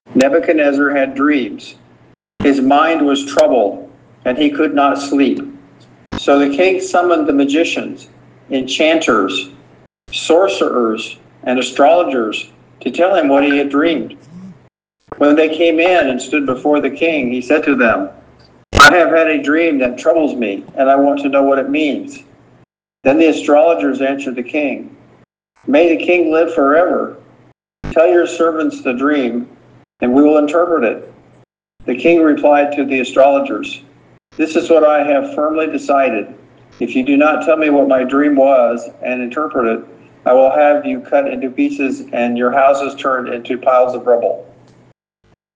* Pronúncia clara
* Ritmo natural do idioma